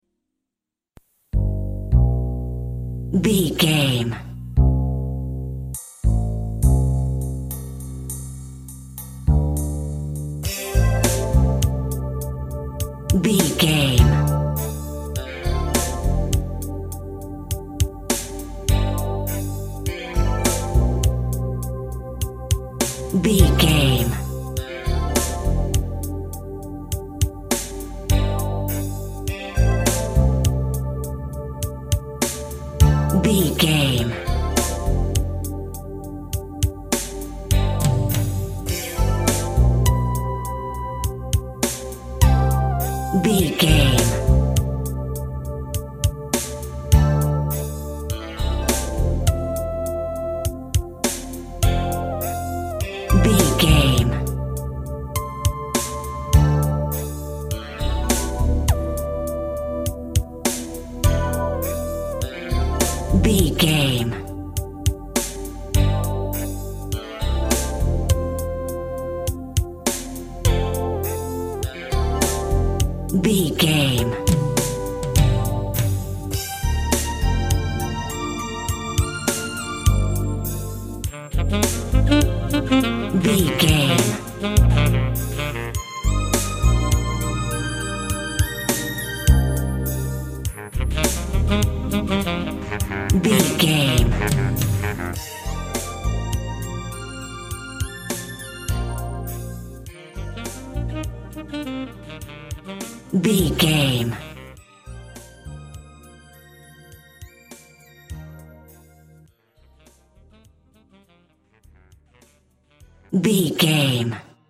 Eighties Cop Film.
Ionian/Major
Slow
groovy
dreamy
peaceful
smooth
drums
bass guitar
synthesiser
electric guitar
80s music
synth bass
synth lead